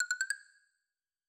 Sound / Effects / UI / Modern13.wav